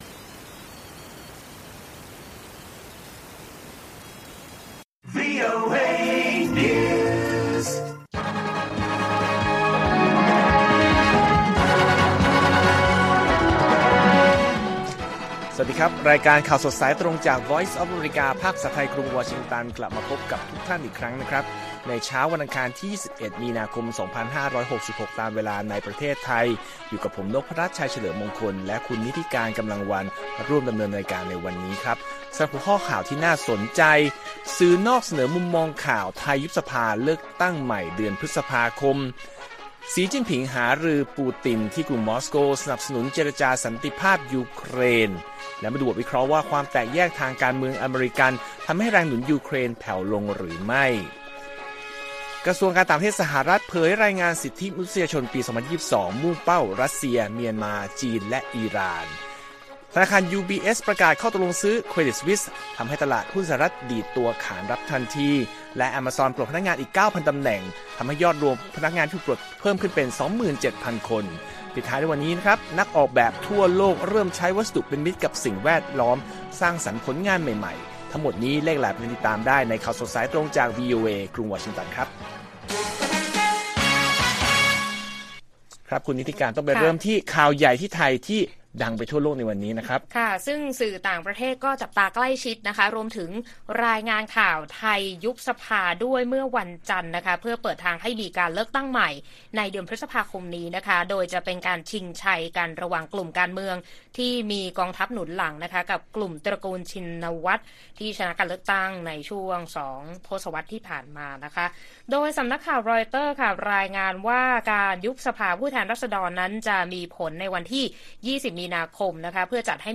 ข่าวสดสายตรงจากวีโอเอไทย 6:30 – 7:00 น. วันที่ 21 มี.ค. 2566